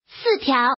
Index of /mahjong_gansu_test/update/1686/res/sfx/woman/